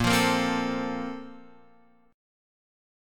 A# Major 7th Suspended 2nd